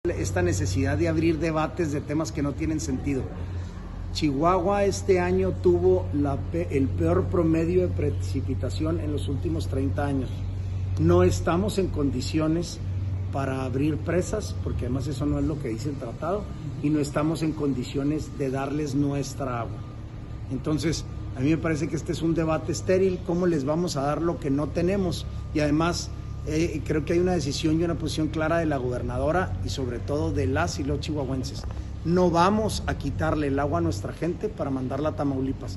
AUDIO: SANTIAGO DE LA PEÑA GRAJEDA, TITULAR DE LA SECRETARÍA GENERAL DE GOBIERNO (SGG)